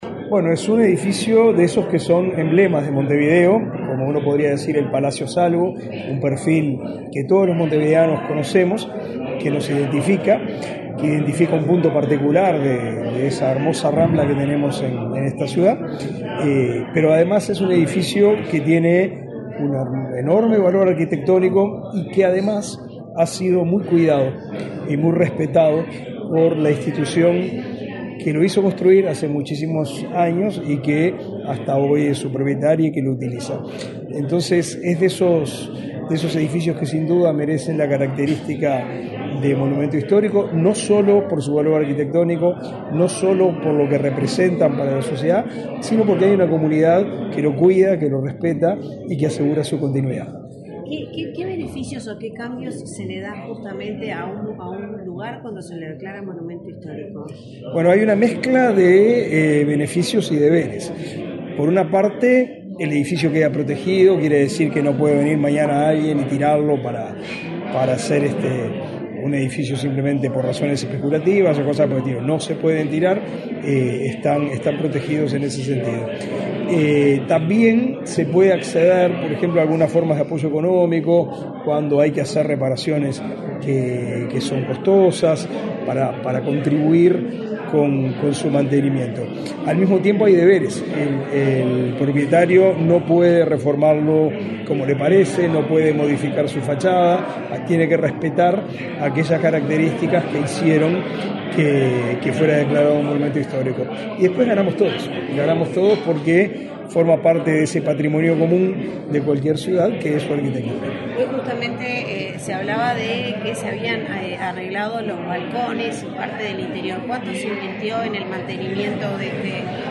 Declaraciones del ministro de Educación y Cultura, Pablo da Silveira
Declaraciones del ministro de Educación y Cultura, Pablo da Silveira 15/08/2024 Compartir Facebook X Copiar enlace WhatsApp LinkedIn El Ministerio de Educación y Cultura declaró monumento histórico nacional al edificio del Yacht Club de Montevideo. El titular de la cartera, Pablo da Silveira, dialogó con la prensa acera del alcance de esta medida.